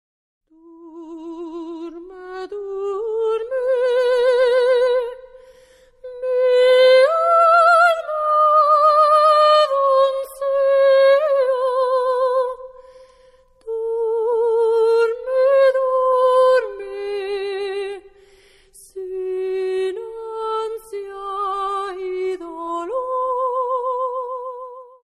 Ladino songs of the Jews from Spain